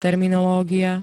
terminológia [t-] -ie pl. G -ií D -iám L -iách ž.
Zvukové nahrávky niektorých slov